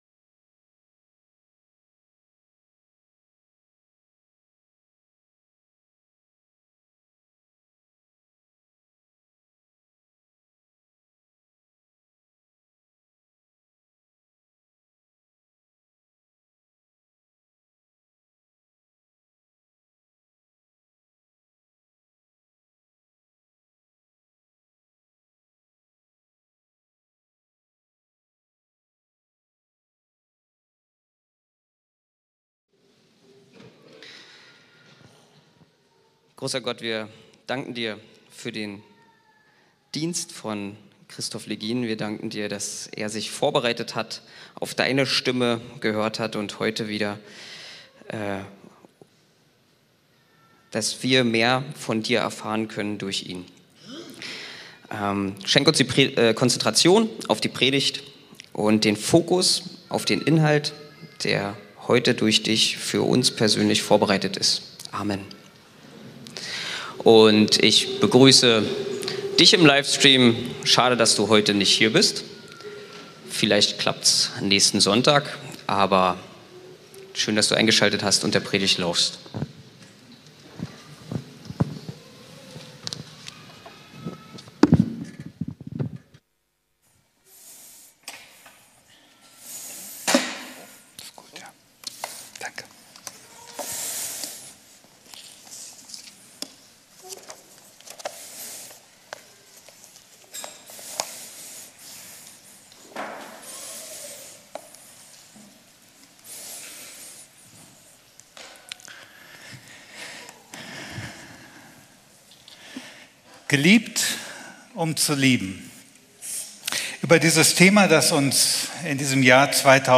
Teil 3 der Predigtreihe zum Jahresmotto 2026